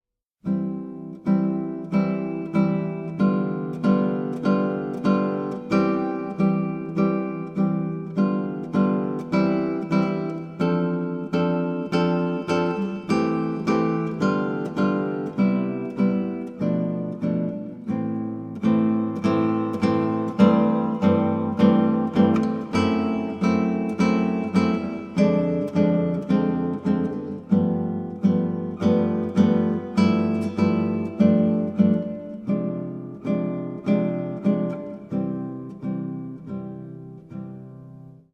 pdfmp3Presti, Ida, Six Etudes for guitar, Étude No. III, mm.1-15(answer key) (link to recording)